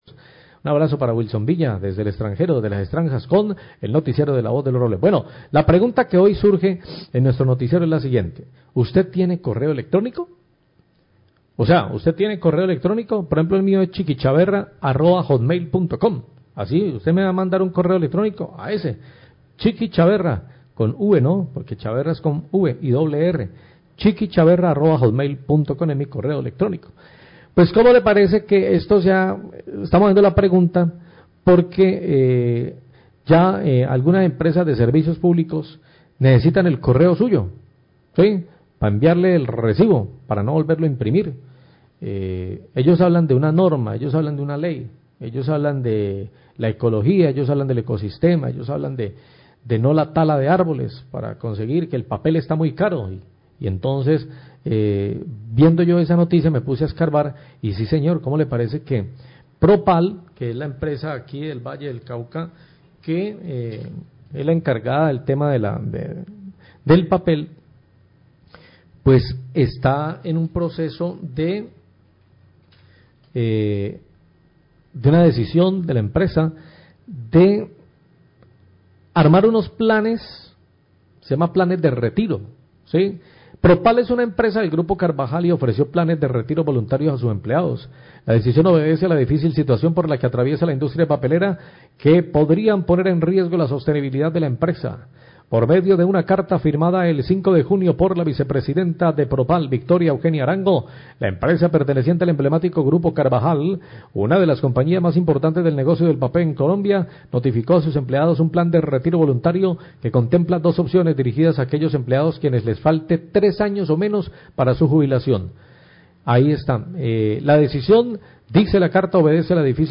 Radio
Pasa entonces a lanzar la encuesta al aire sobre que opinan los usuarios sobre esta medida del cobro a través de correo electrónico.